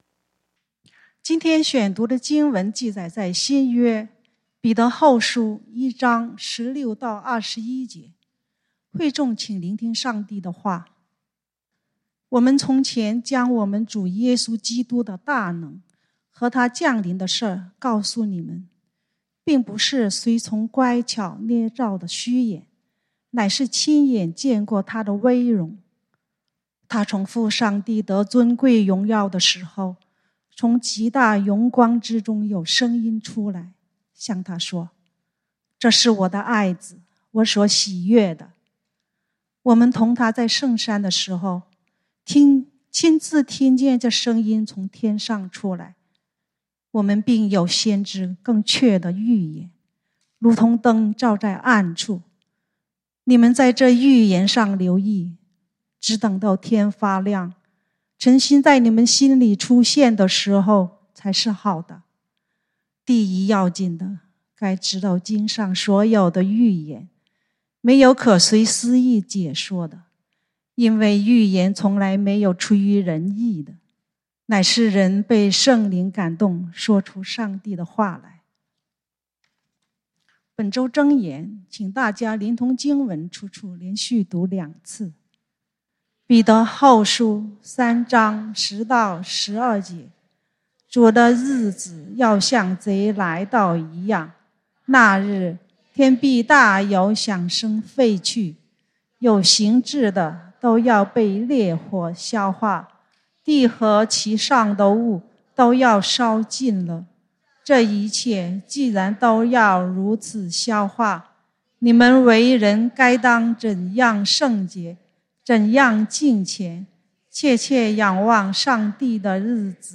講道經文：彼得後書 2 Peter 1:16-21 本週箴言：彼得後書 3:10-12 「主的日子要像賊來到一樣。